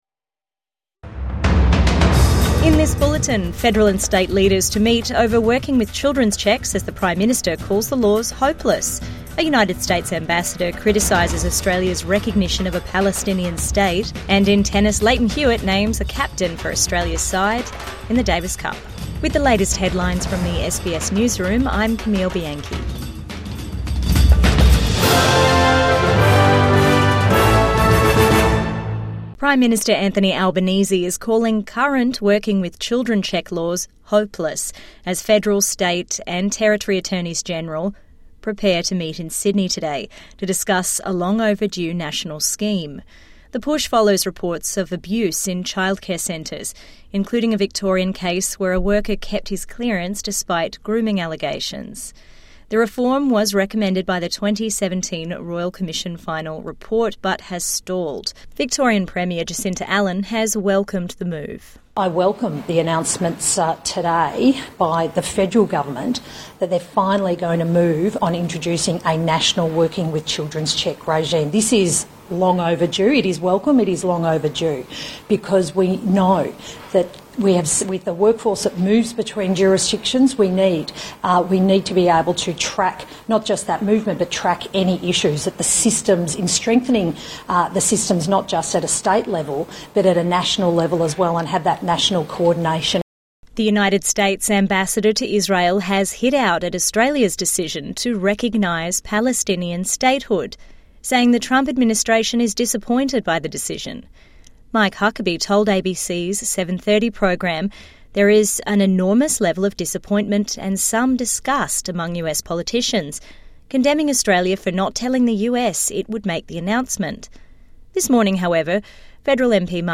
Children's checks reform on agenda for attorneys general | Midday News Bulletin 15 August 2025 6:03